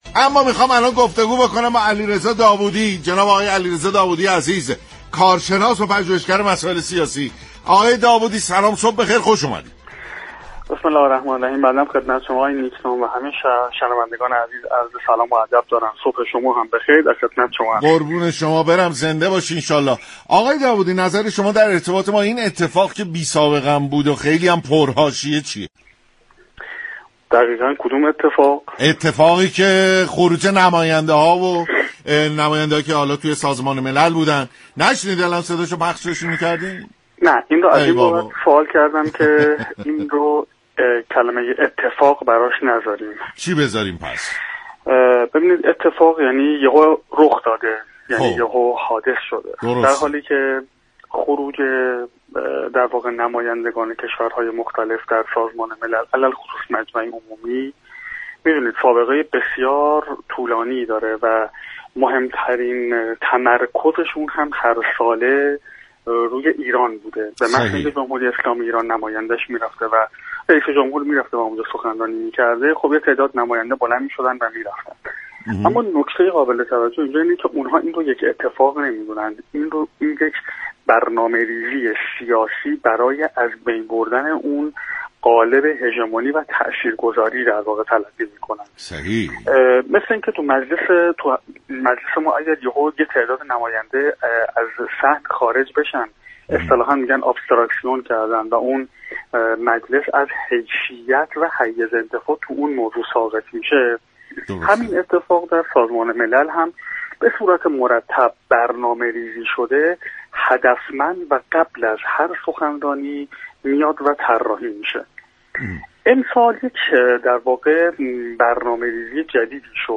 كارشناس مسائل سیاسی در برنامه سلام‌صبح‌بخیر
برنامه سلام صبح بخیر شنبه تا پنج‌شنبه ساعت 6:15 از رادیو ایران پخش می‌شود.